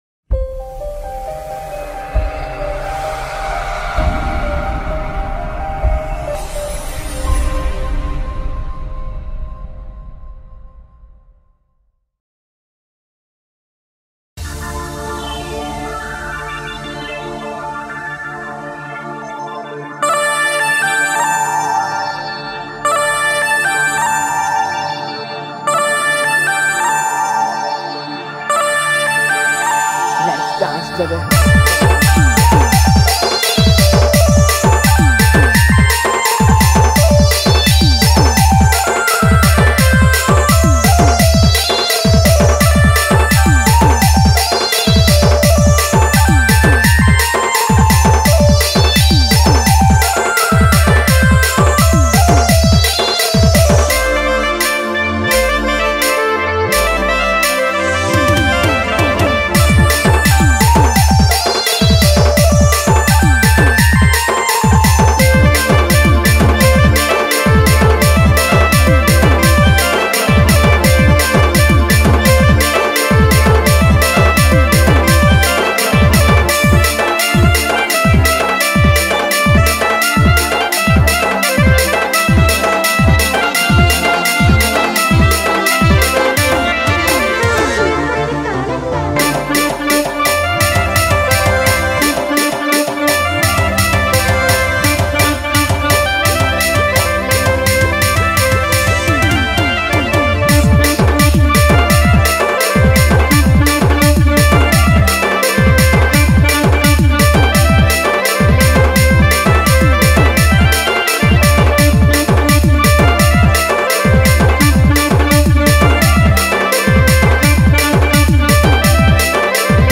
Instrumental Music And Rhythm Track Songs Download